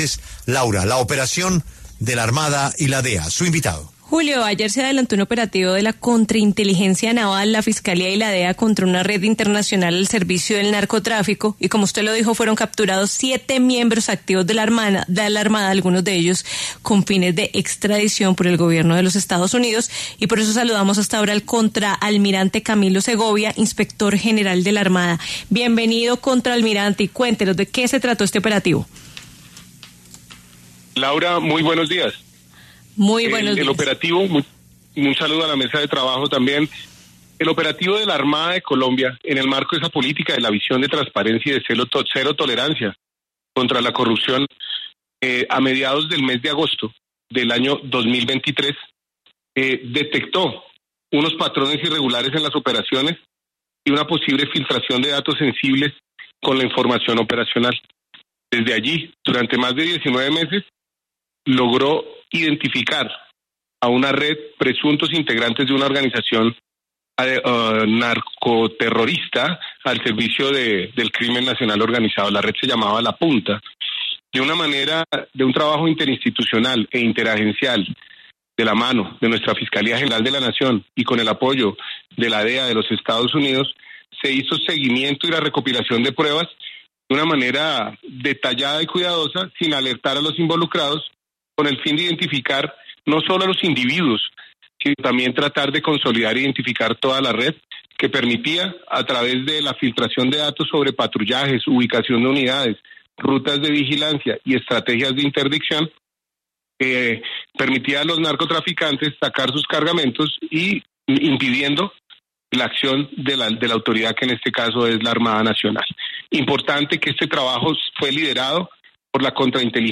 El contraalmirante Camilo Segovia, inspector general de la Armada Nacional, habló en La W sobre la captura de siete miembros activos de la fuerza.